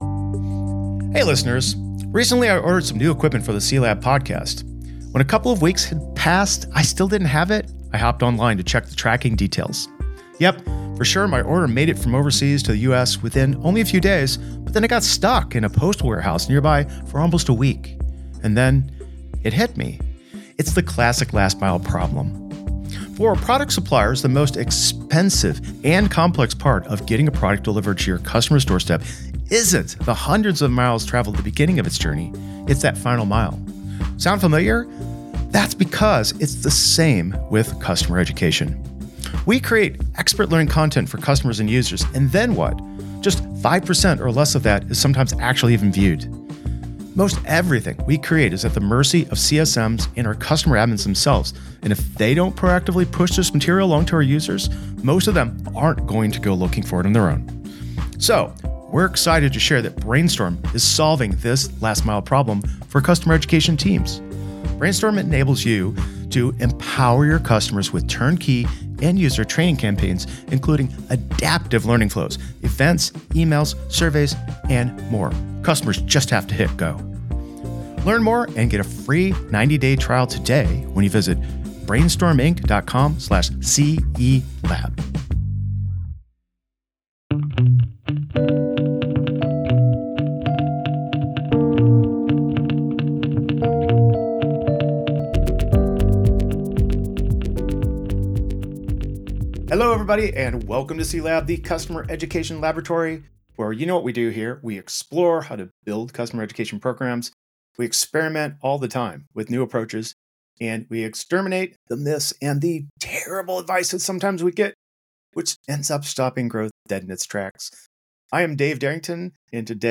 What does the future hold for Customer Education in the ever-evolving world of B2B SaaS? In this solo episode